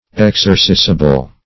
Meaning of exercisible. exercisible synonyms, pronunciation, spelling and more from Free Dictionary.
Exercisible \Ex"er*ci`si*ble\, a.